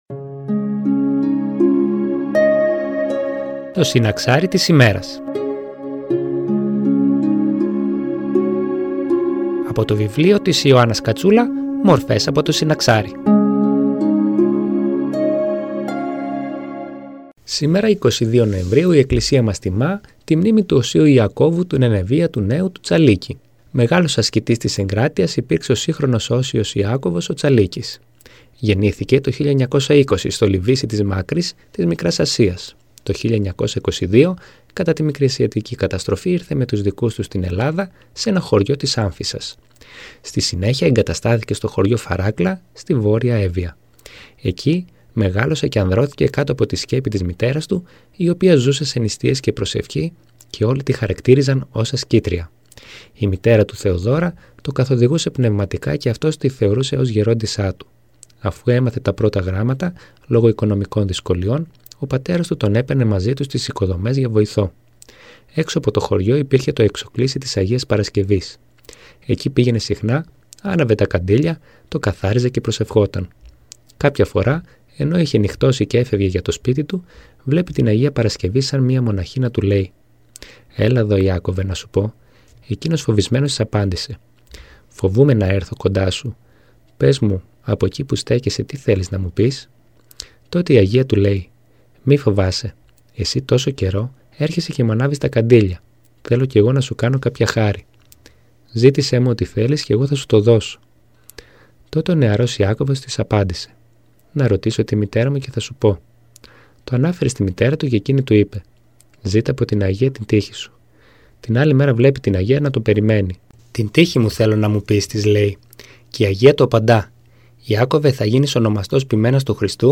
Μια ένθετη εκπομπή που μεταδίδονται καθημερινά οι βίοι των αγίων που εορτάζονται από την Εκκλησία μας, από το βιβλίο της Ιωάννας Κατσούλα «Μορφές από το συναξάρι».
Εκκλησιαστική εκπομπή